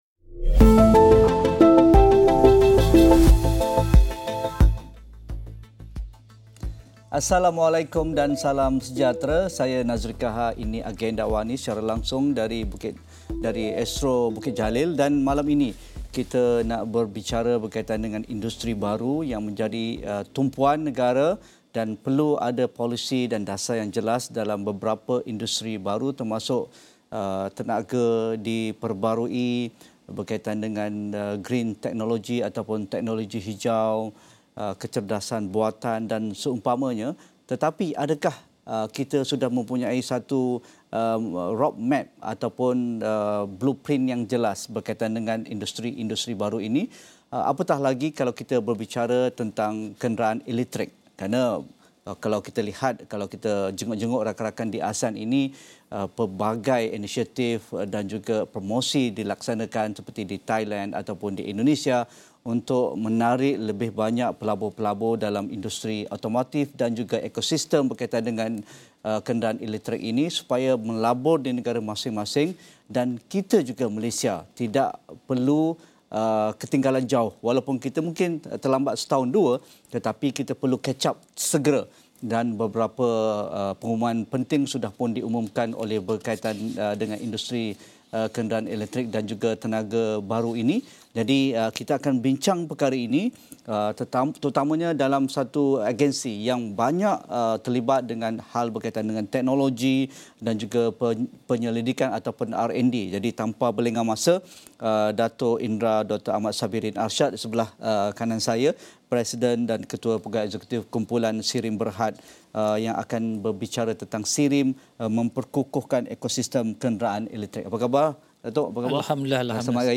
Diskusi 9 malam.